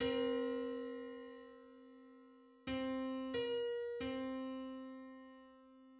Just: 931/512 = 1035.16 cents.
Public domain Public domain false false This media depicts a musical interval outside of a specific musical context.
Nine-hundred-thirty-first_harmonic_on_C.mid.mp3